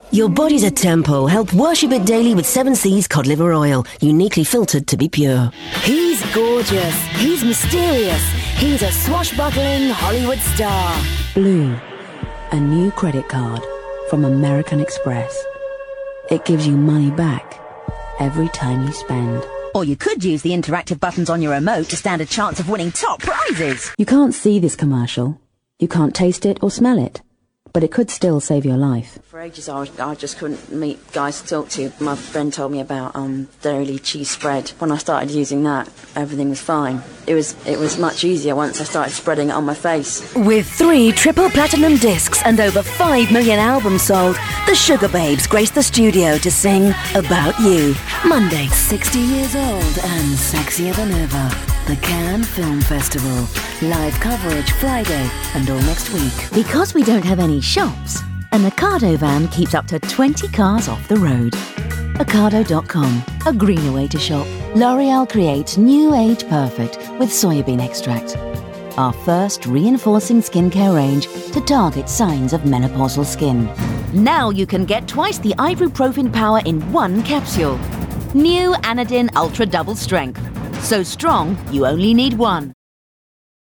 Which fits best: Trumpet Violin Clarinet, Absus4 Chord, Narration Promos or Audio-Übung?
Narration Promos